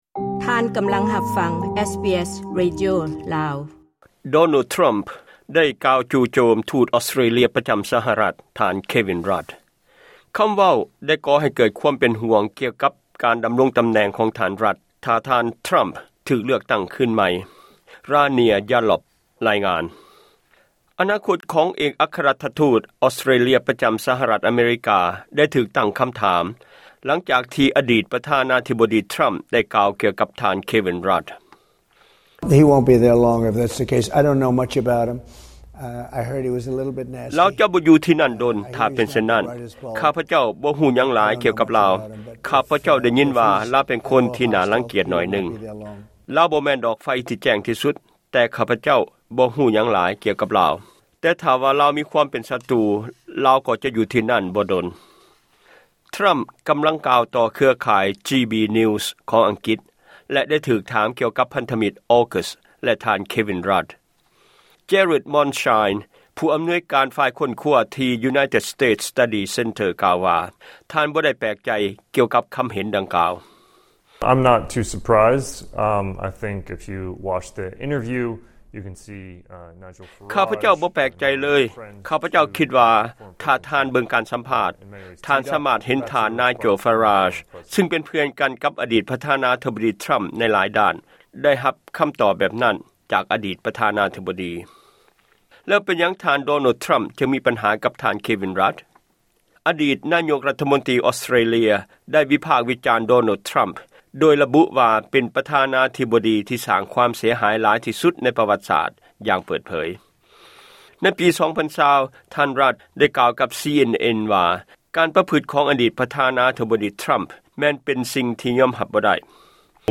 ລາຍງານ.